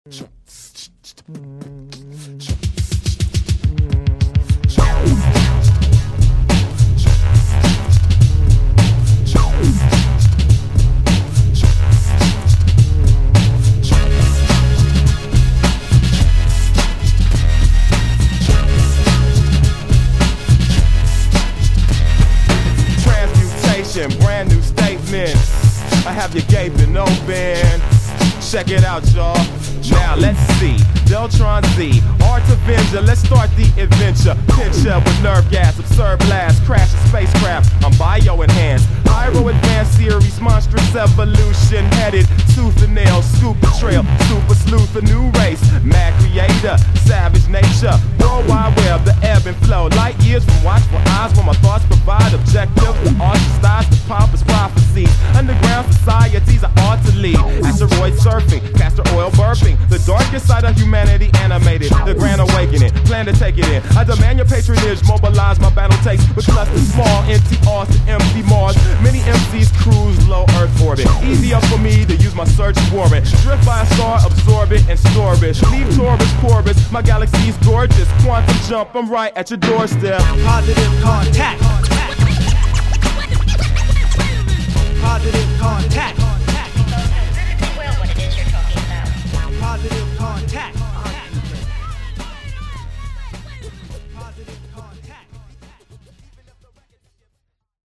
to futuristic techno funk
lacing scratches and vocal samples throughout every song